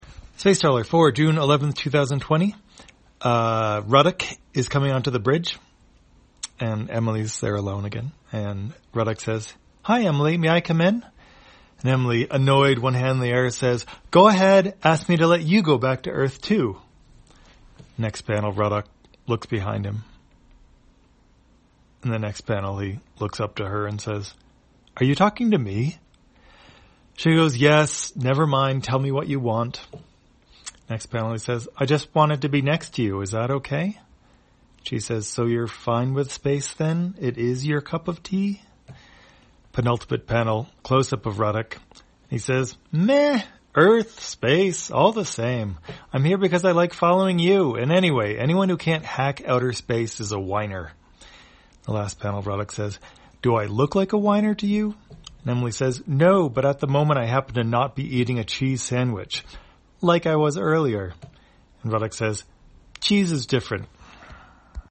Spacetrawler, audio version For the blind or visually impaired, June 11, 2020.